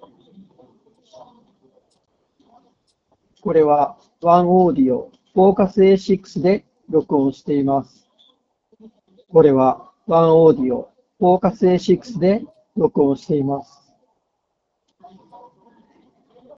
OneOdio Focus A6の通話時ノイキャン
スピーカーから雑踏音をそこそこ大きなボリュームで流しながらマイクで収録した結果がこちら。
背景で鳴っている雑音が小さいですよね？
oneodio-focus-a6-voice.m4a